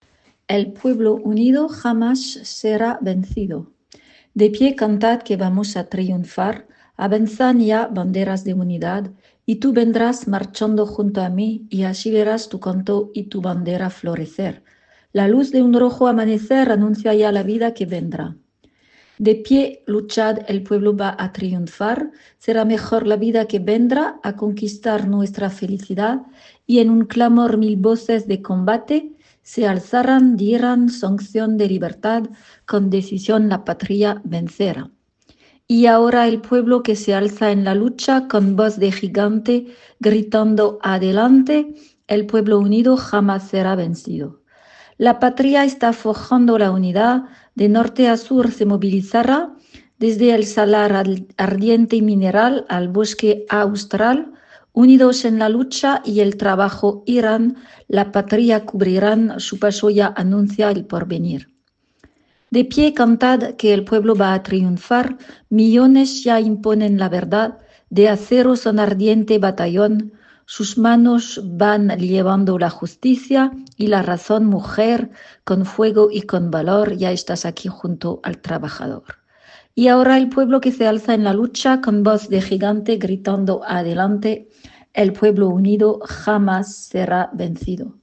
El-pueblo-prononciation
el-pueblo-prononciation-2.mp3